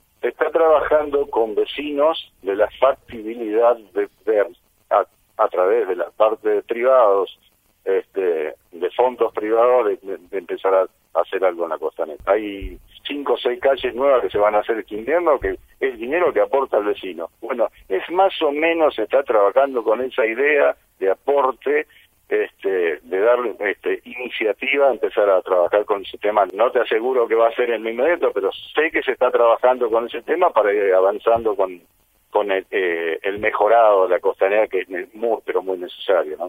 En diálogo con Radio 3, el director Alejandro Trybuchowicz indicó que se reencarpetarán seis calles de la localidad hacia mayo, pagadas por los frentistas, y que ese esquema podría reeditarse para la arteria costera pero aún sin fecha certera de desarrollo.